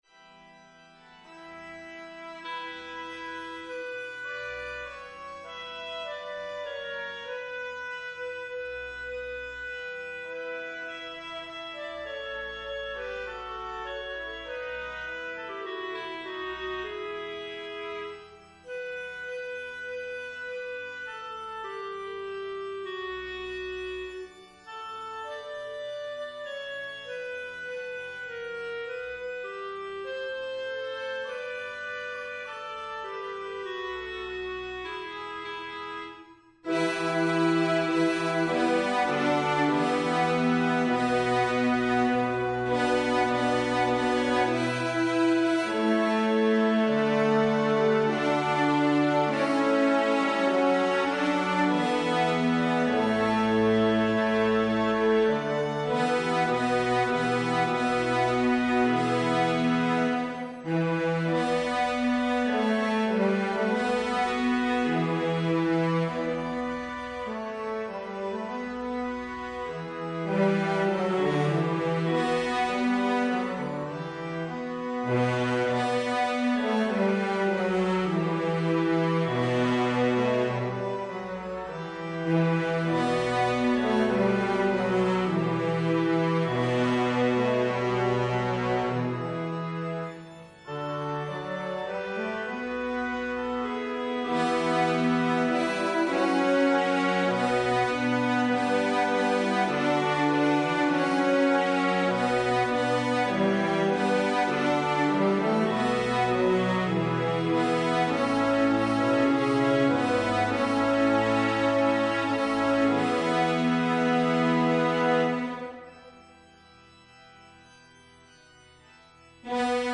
Tenor
Evensong Setting